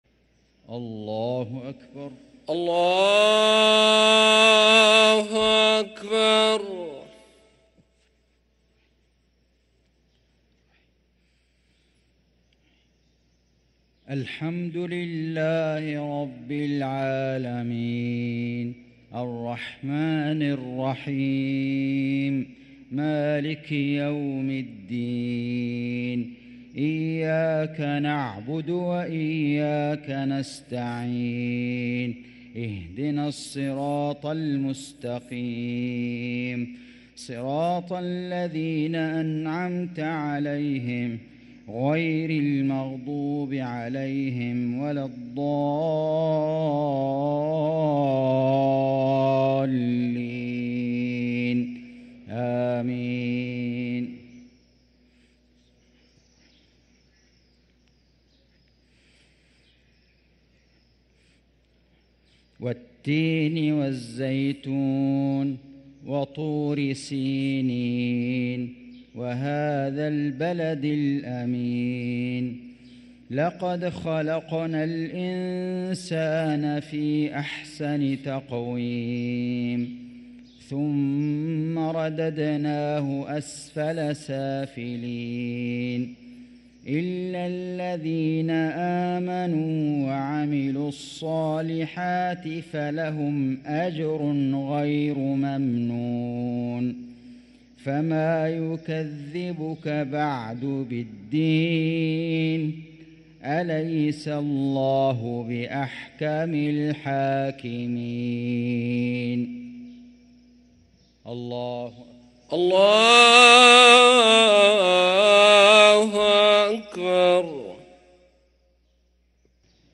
صلاة المغرب للقارئ فيصل غزاوي 9 جمادي الآخر 1445 هـ
تِلَاوَات الْحَرَمَيْن .